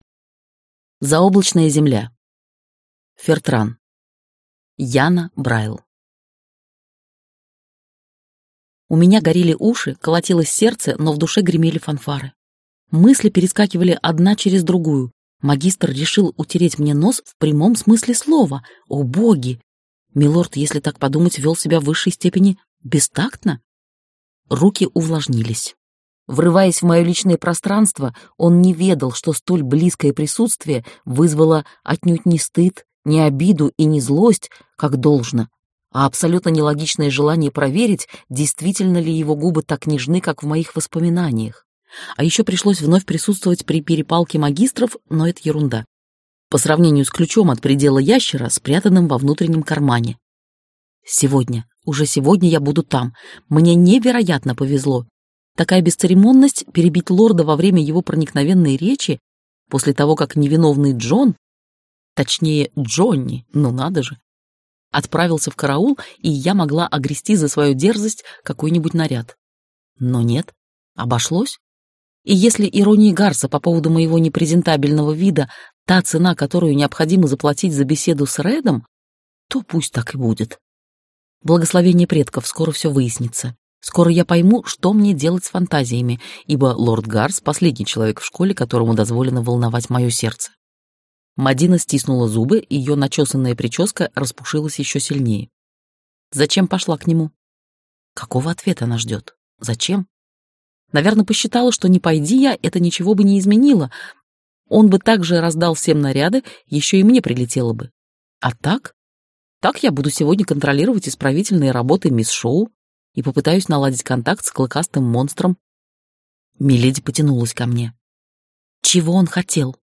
Аудиокнига Шпионка поневоле | Библиотека аудиокниг